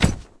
drop.wav